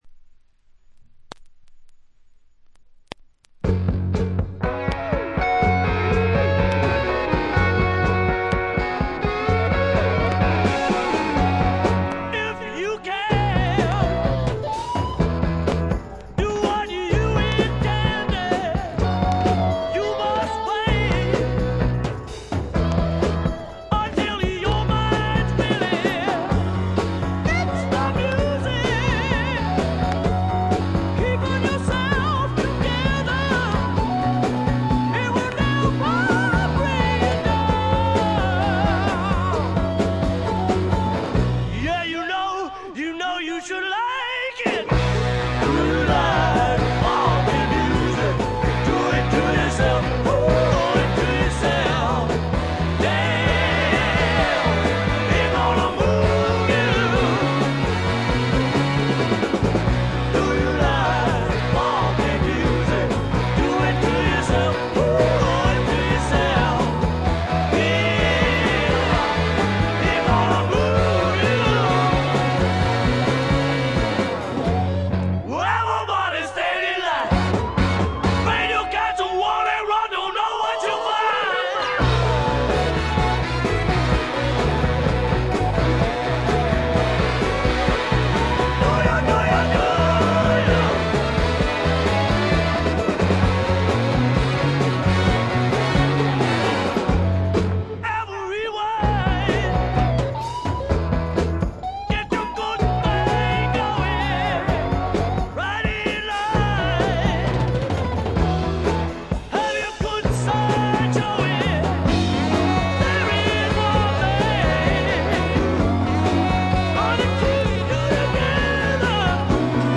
泣けるバラードからリズムナンバーまで、ゴスペル風味にあふれたスワンプロック。
試聴曲は現品からの取り込み音源です。